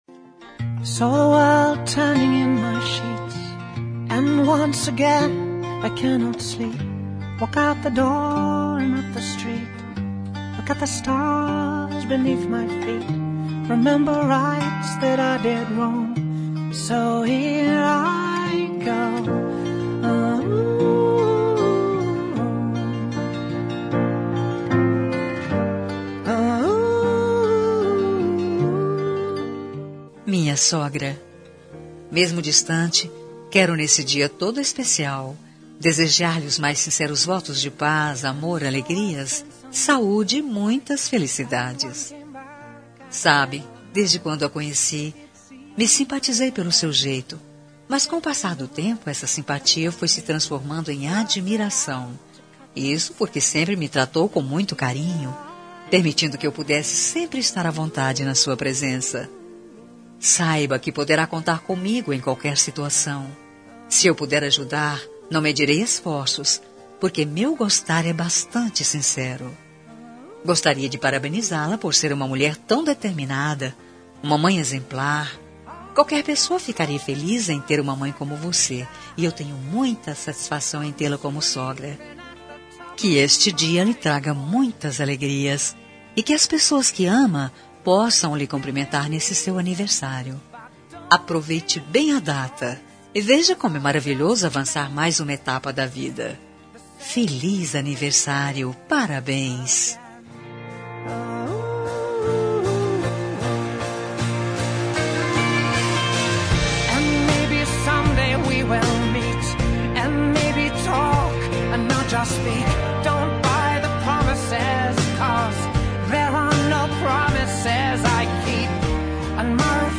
Telemensagem de Aniversário de Sogra – Voz feminina – Cód: 1969 – Distante